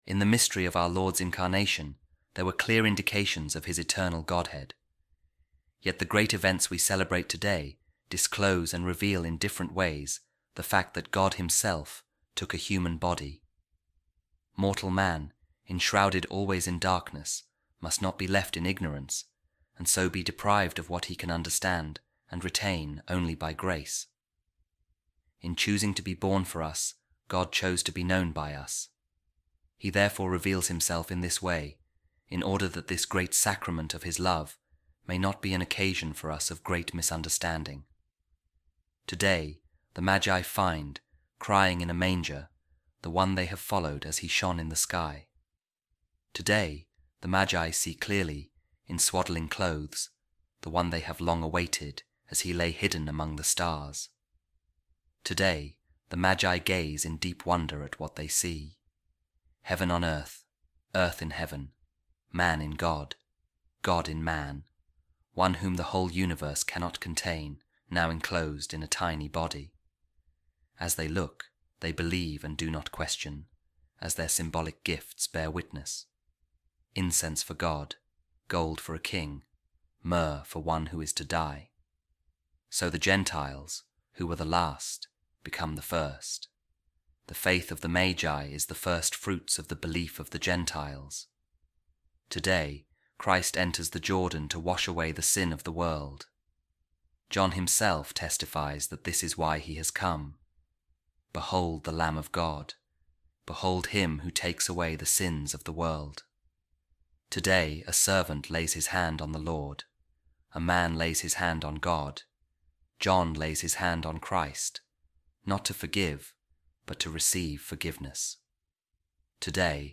A Reading From The Sermons Of Saint Peter Chrysologus | In Choosing To Be Born For Us, God Chose To Be Known By Us